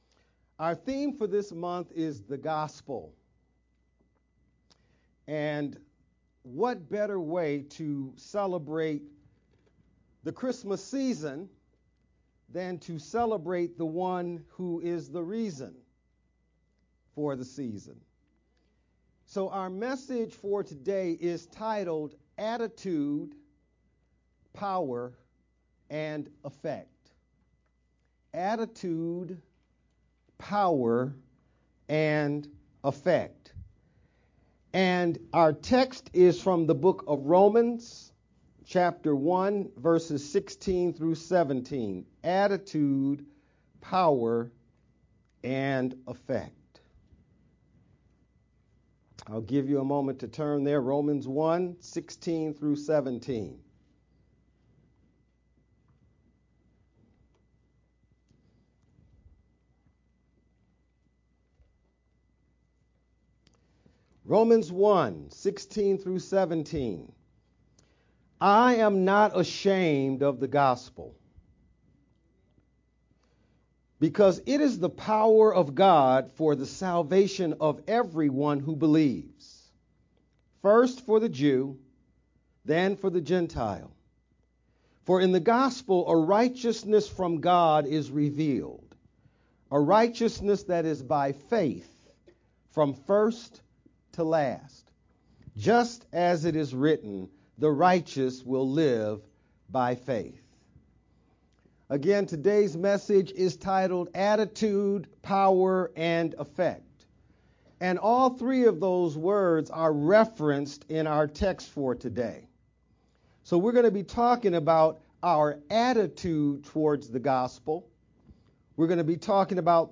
Dec-3rd-VBCC-sermon-only_Converted-CD.mp3